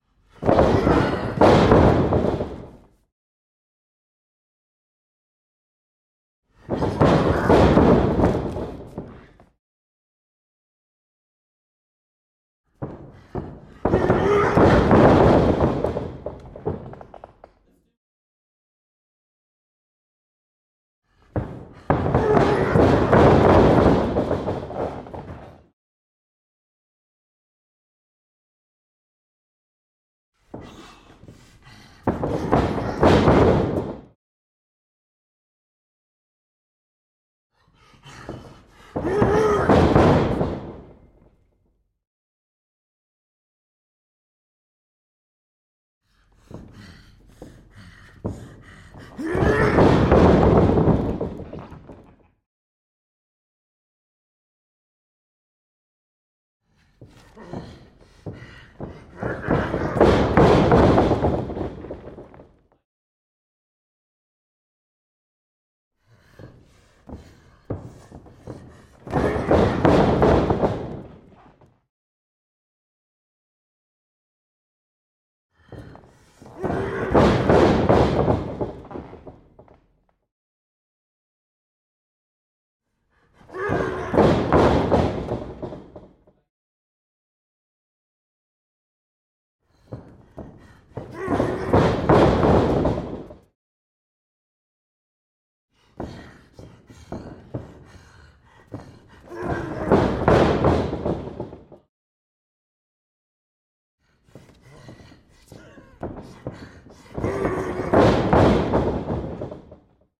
随机 " 摔跤手在擂台上大跑的脚步声+沉重的呼吸声
Tag: 脚步声 摔跤 呼吸